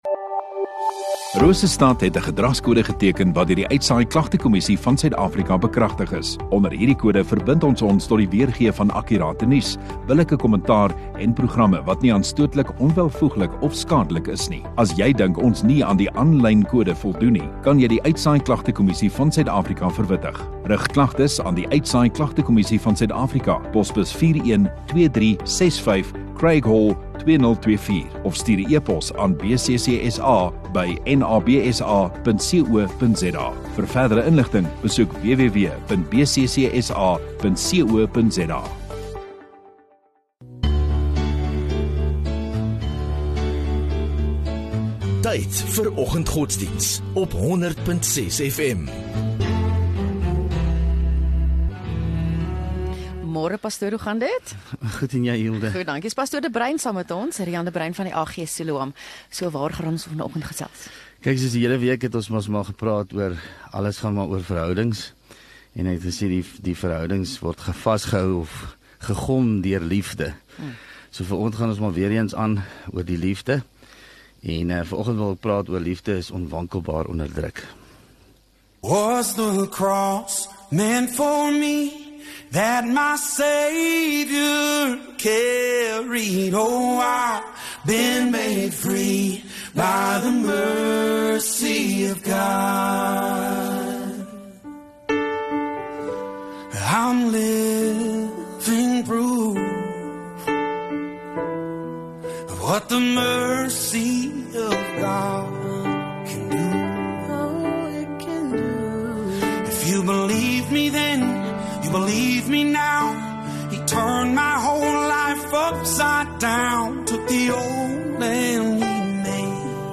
18 Jul Donderdag Oggenddiens